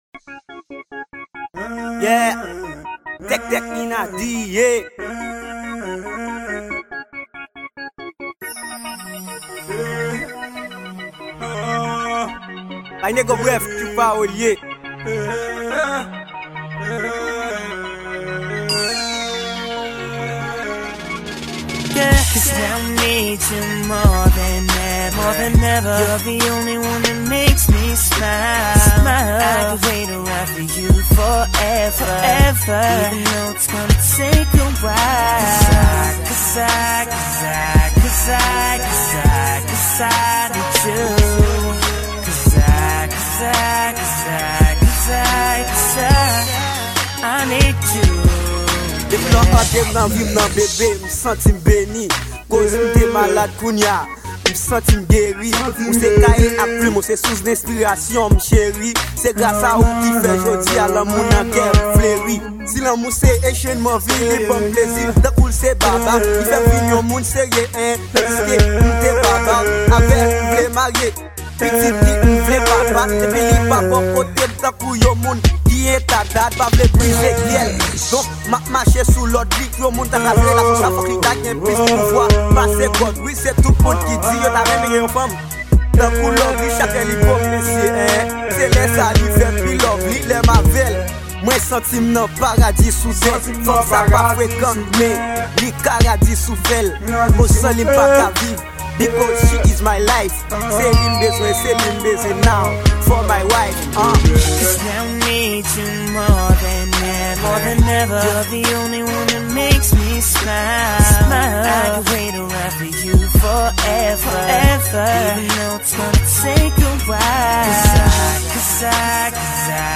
Genre: RAAP.